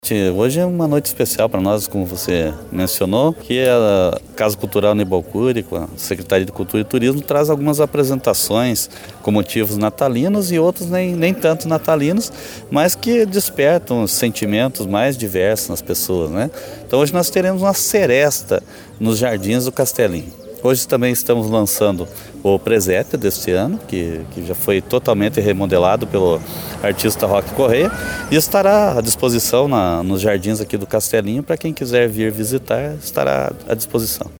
O secretario de Cultura e Turismo de Porto União José Carlos Gonçalves, esteve prestigiando o evento é destacou a importância da seresta em prol de trazer cultura para a comunidade.
SERENATA-DE-NATAL-PARTE-01-SECRETARIO-JOSÉ-CARLOS-FALA-DO-EVENTO-E-RESSALTA-A-REFORMA-DO-PRESÉPIO-NO-CASTELINHO.mp3